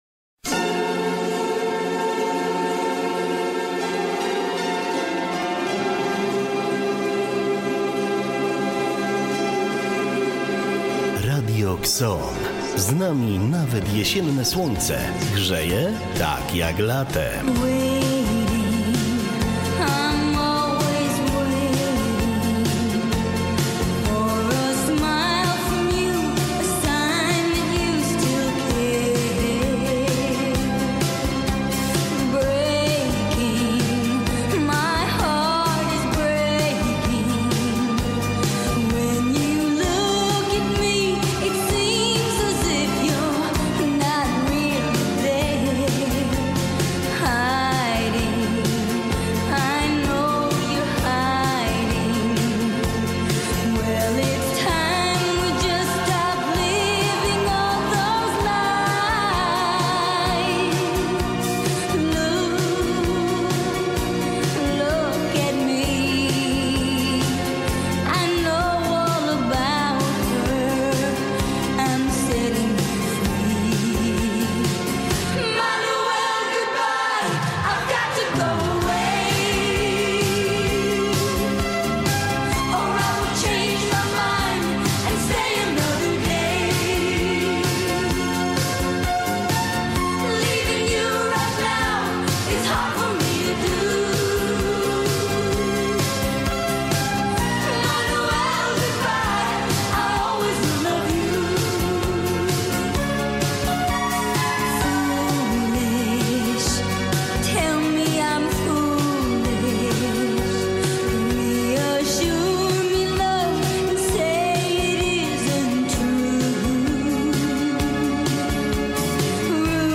Prezenterzy Radia KSON w Marchewkowym Studiu omawiają fascynujący świat kawy.